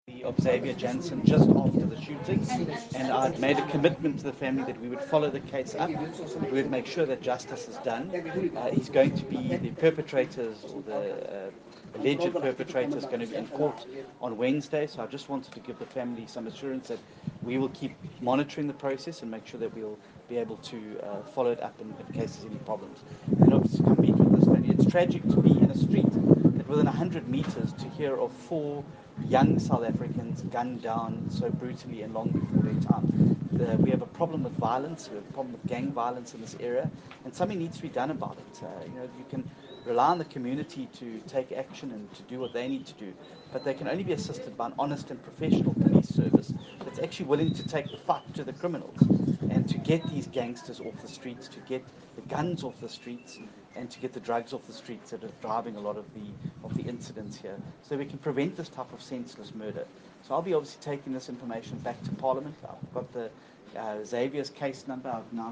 English soundbite by John Steenhuisen and please find more pictures here, here and a video clip here